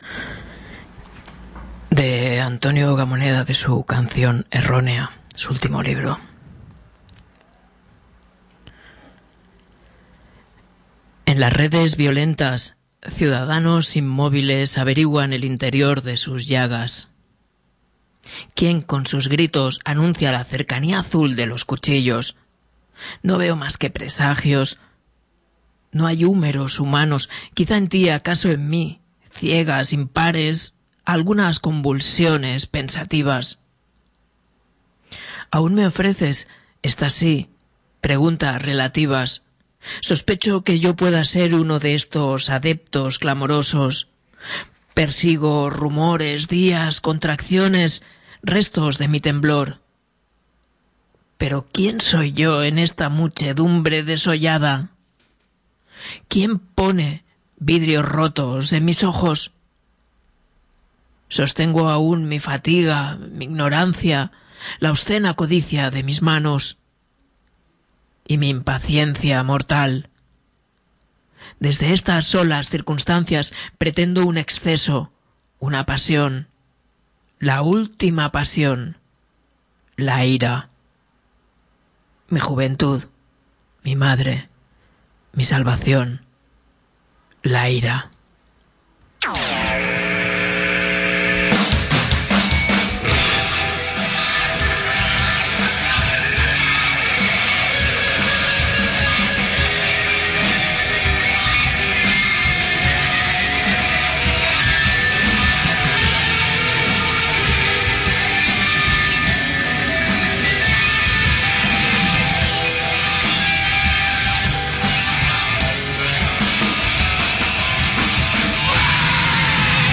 Leemos uno de sus fragmentos iniciales.